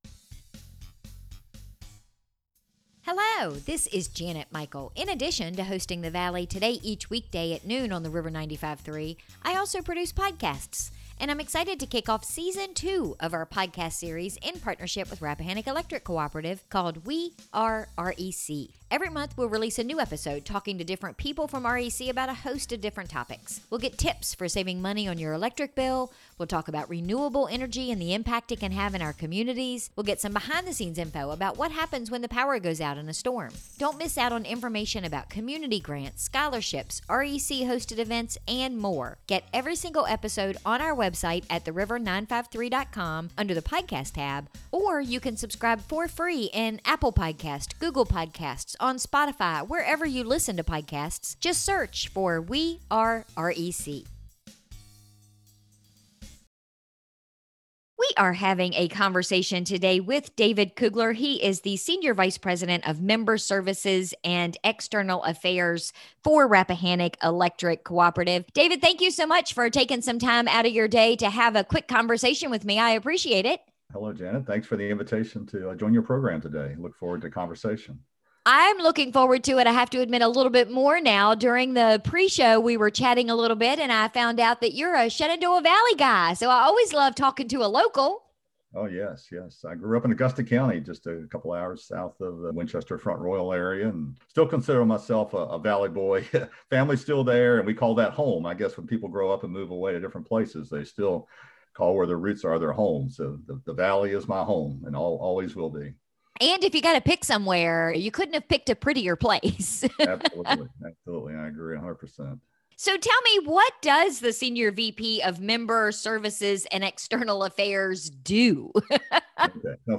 We recorded today’s episode via Zoom